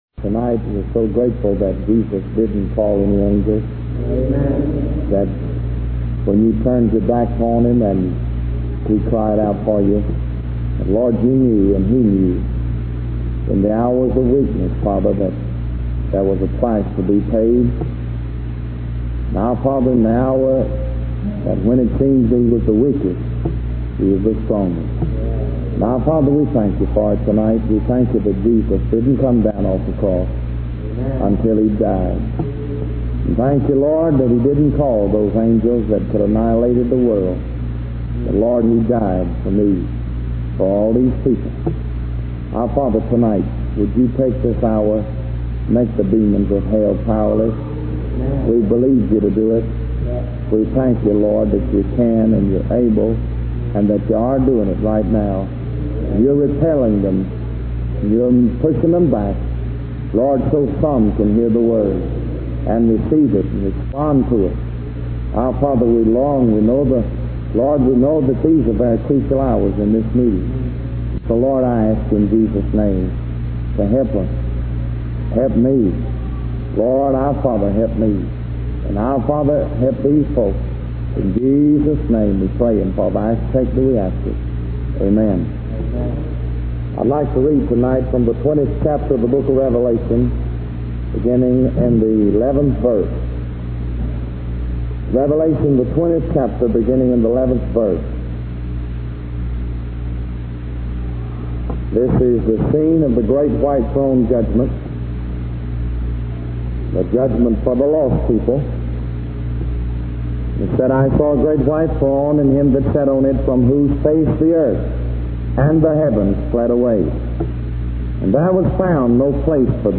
In this sermon, the preacher emphasizes the importance of recognizing and reaching out to those who are lost and in need of salvation. He emphasizes that God has placed us in families and neighborhoods for a purpose, and it is our responsibility to share the message of Jesus Christ with others. The preacher warns of the consequences of not accepting Jesus as the Son of God and the need for salvation.